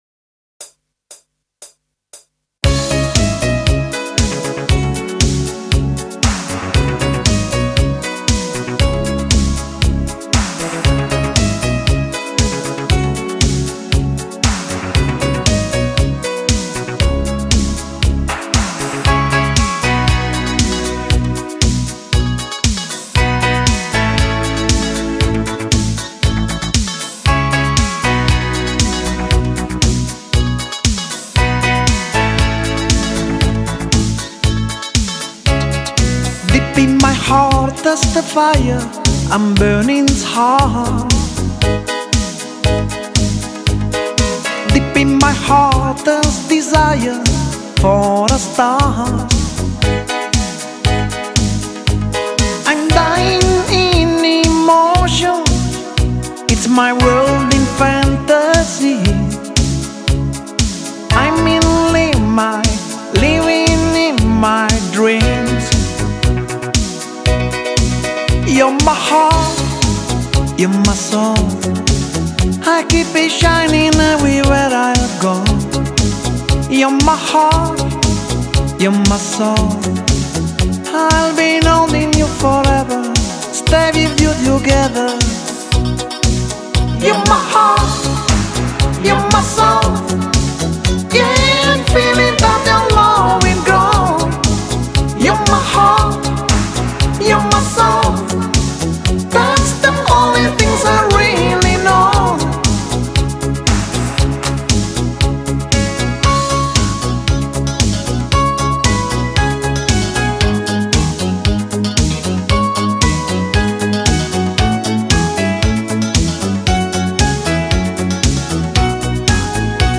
Medley
Gesungen